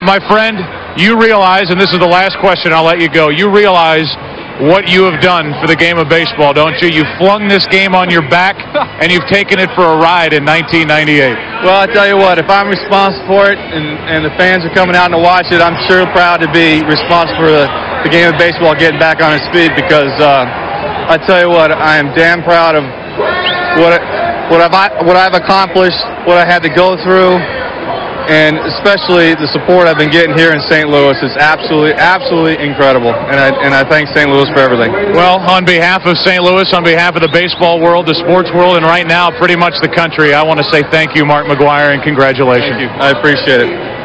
RA Audio: 62nd Home Run Interview 2 - 09 AUG 1999